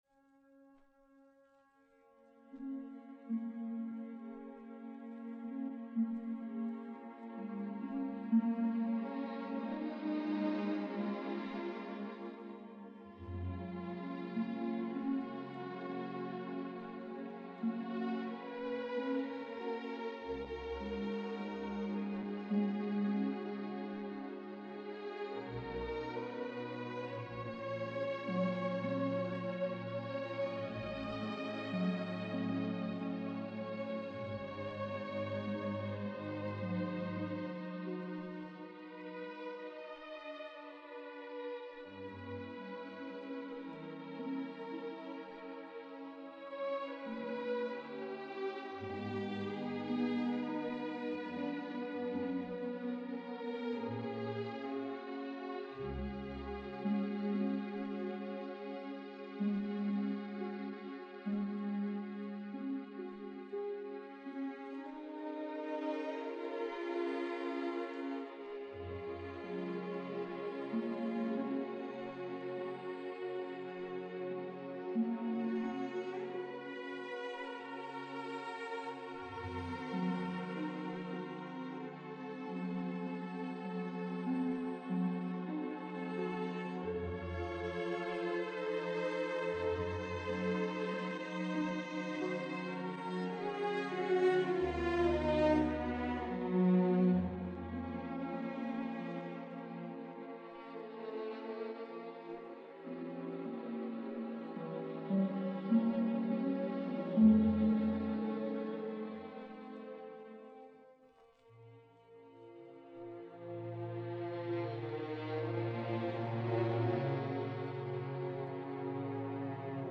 Малер Г. Симфония № 5 cis-moll, 4 часть Adagietto. Sehr langsam
Симфонический оркестр Баварского радио, дир. Р.Кубелик